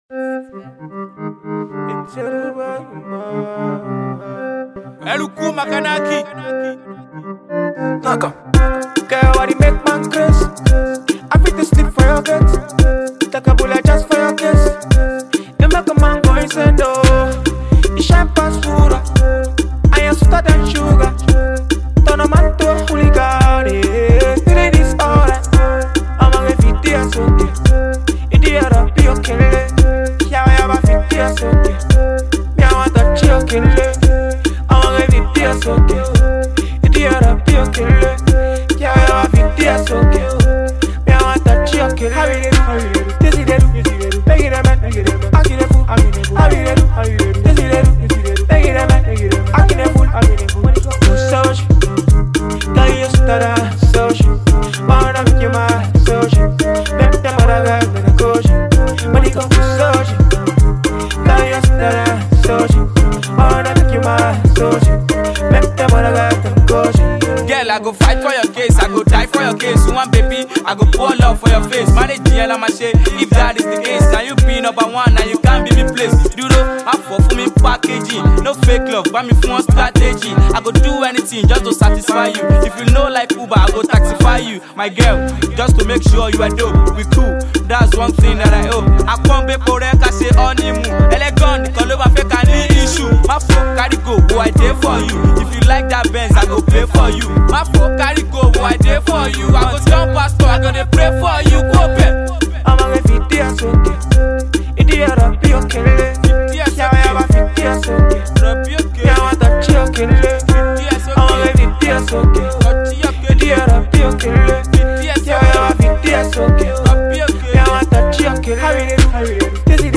Nigerian Afro-pop
street-pop